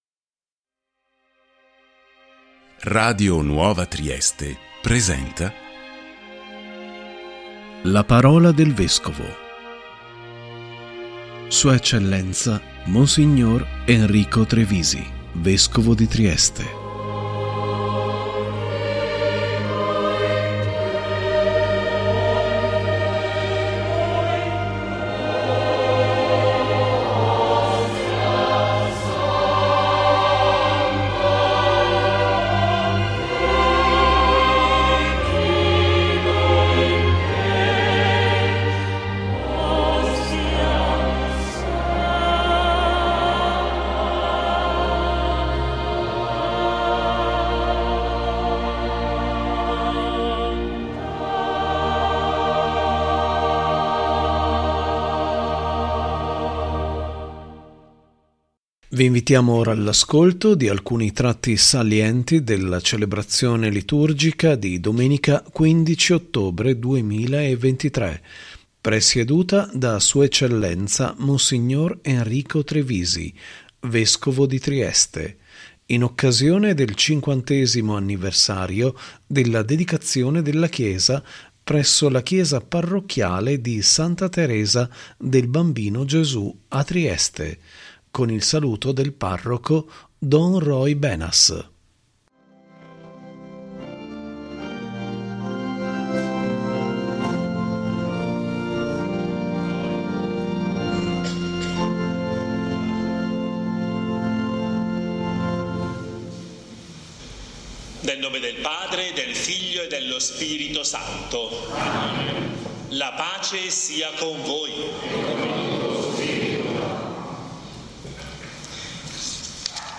♦ si è tenuta, la celebrazione liturgica di Domenica 15 ottobre 2023 presieduta da Sua Eccellenza Mons. Enrico Trevisi, Vescovo di Trieste, in occasione del 50° anniversario della Dedicazione della chiesa presso la Chiesa Parrocchiale di Santa Teresa del bambino Gesù a Trieste